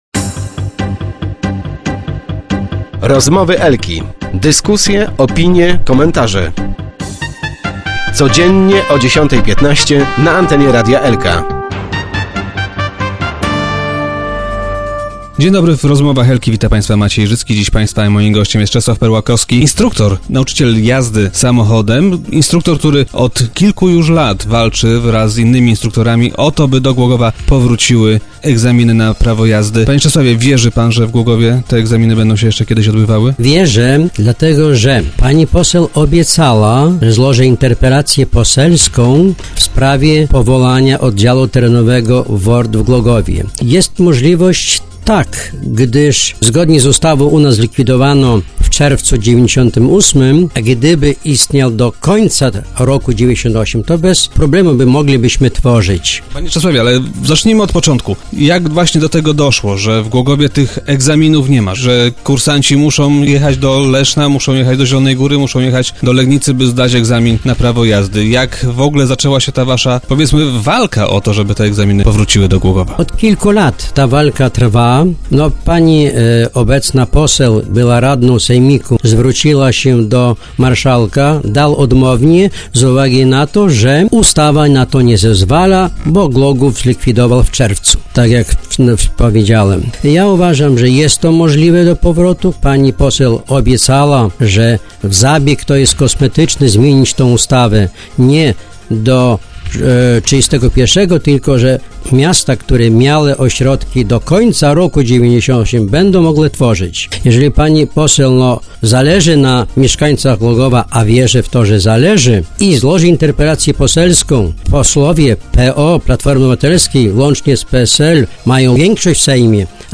Start arrow Rozmowy Elki arrow Liczą na działania posłanki